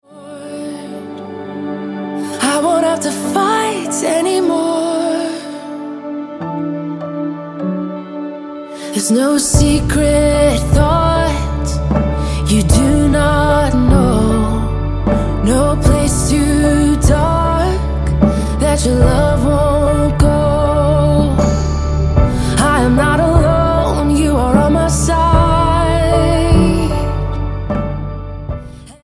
STYLE: Pop
characteristically powerful vocals take centre stage here